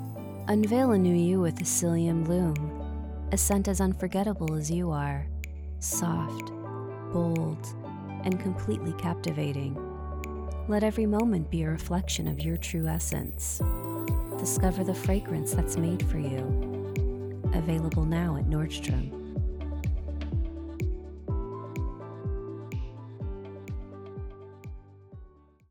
Voice-over artist with a warm, articulate, and soothing voice that brings calm and clarity to every project
Fragrance Ad